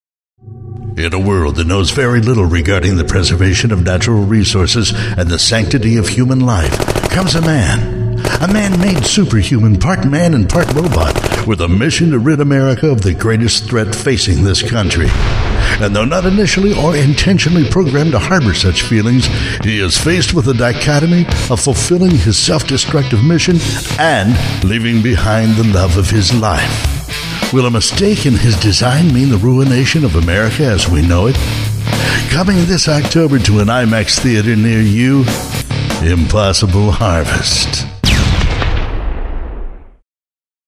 Male
My voice ranges from deep Bass to Baritone.
Movie Trailers
Low Budget Movie Trailer
Words that describe my voice are Deep, Southern, Cowboy.